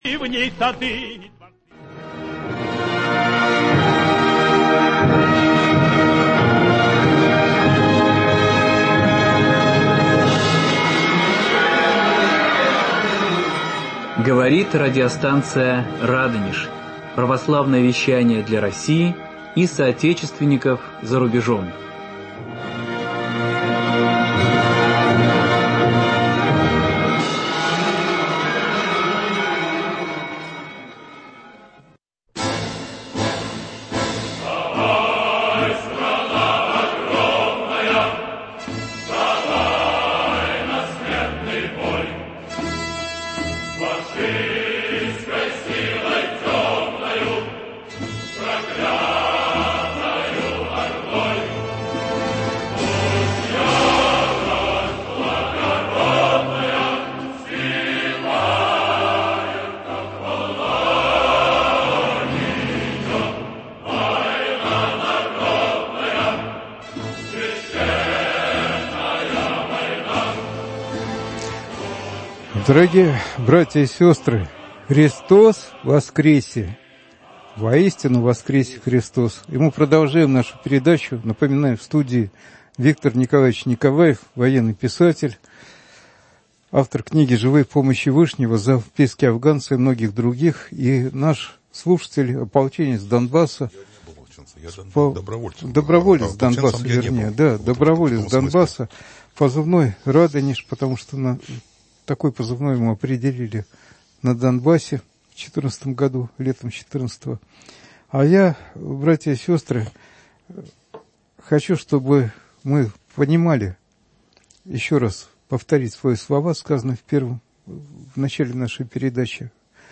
Прямой эфир, посвященный празднованию Великой Победы.